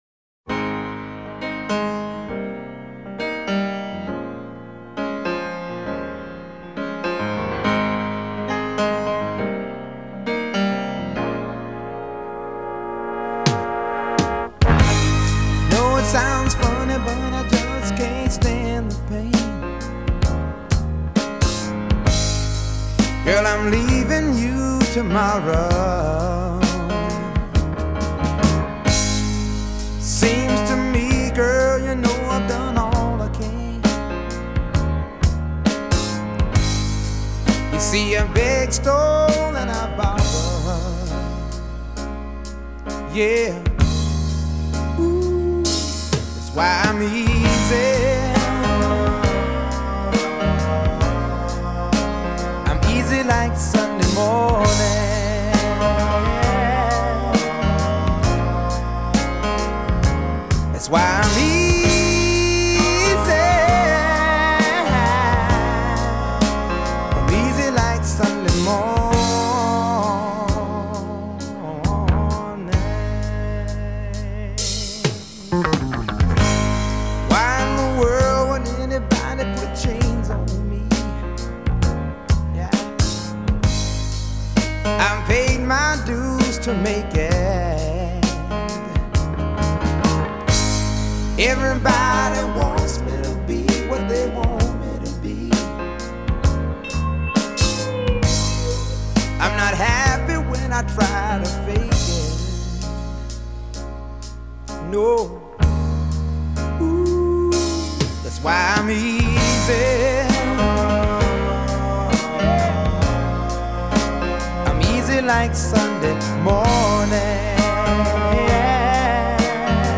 Funk, el género musical de los 60